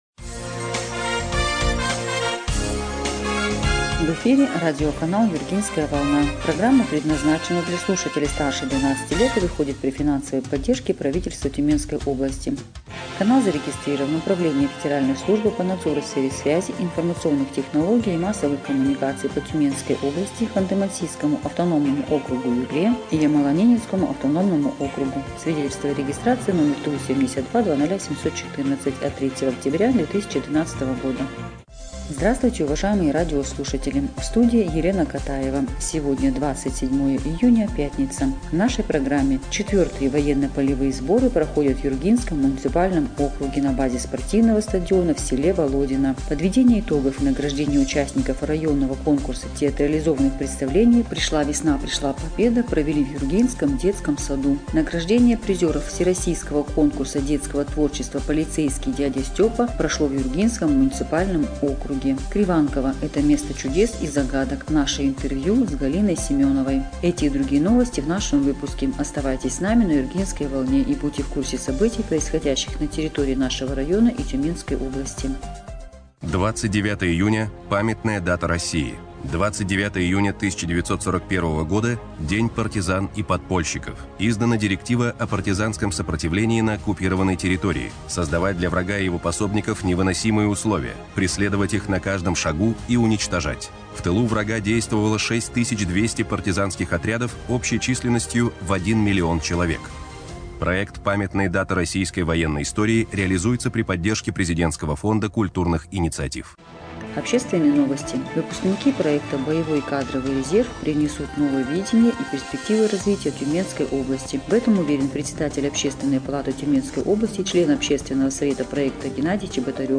Эфир радиопрограммы "Юргинская волна" от 27 июня 2025 года